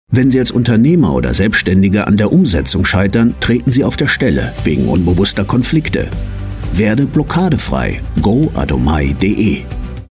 Funkspot_Adomai-Life-Changing-Experience-e.K.-10-Sek.mp3